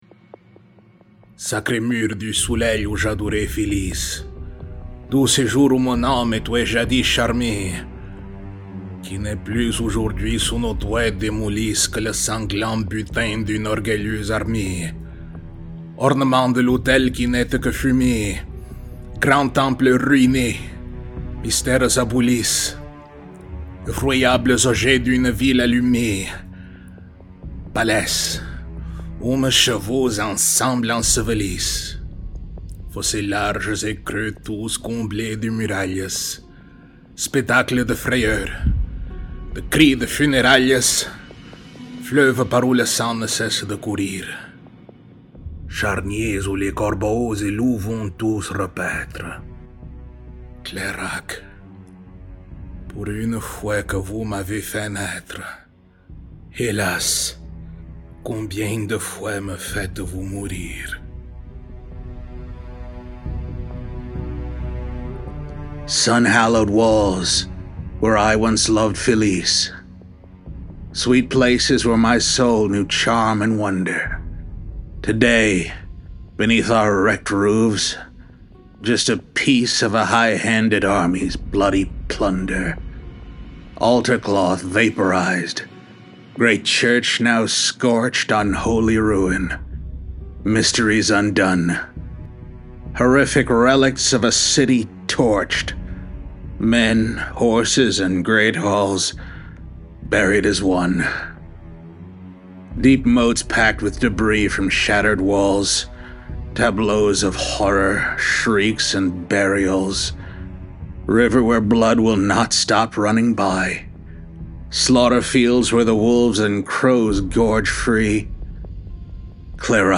To hear the original text read in a reconstruction of early 17th century French pronunciation (as well as a reading of the translation) listen to the accompanying audio file.
Lament-for-Clairac-French-and-English.mp3